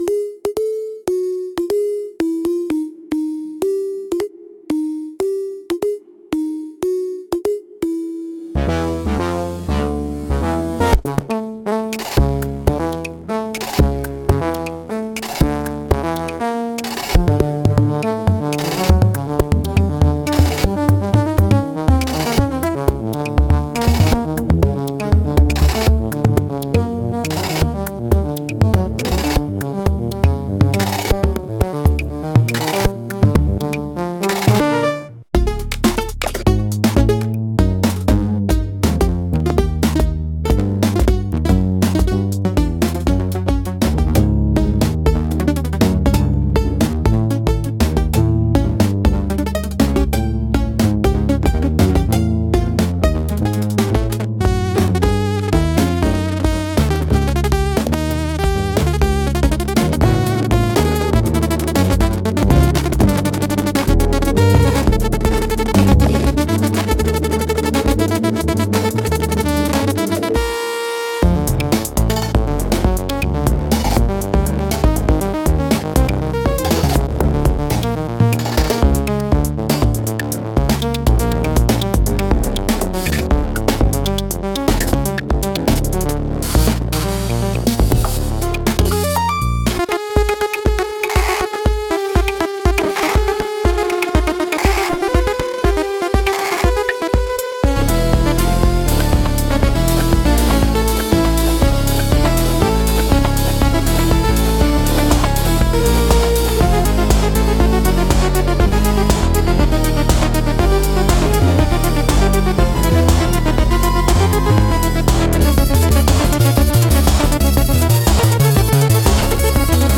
So, I finally convinced AI to perform serial music.
It's good at that, but you can modify midi to be strict and work with AI to create an atonal sound.